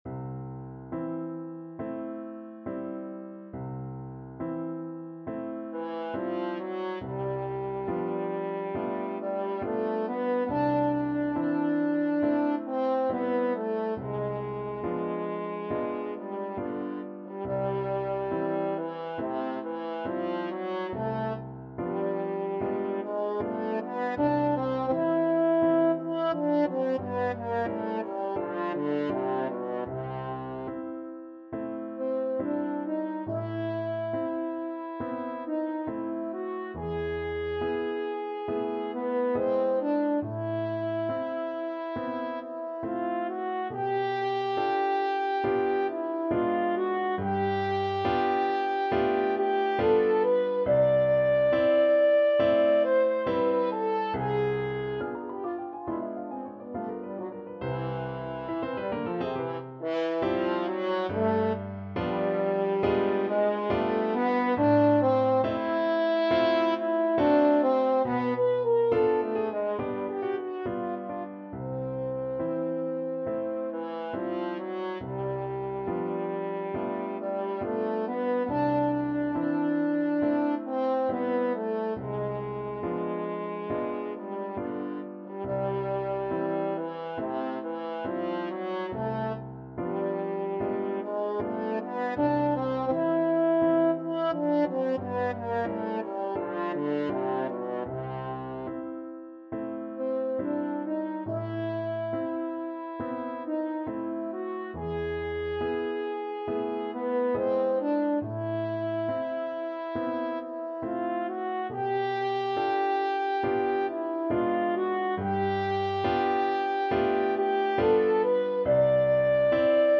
French Horn
C major (Sounding Pitch) G major (French Horn in F) (View more C major Music for French Horn )
4/4 (View more 4/4 Music)
Andante non troppo con grazia =69
Classical (View more Classical French Horn Music)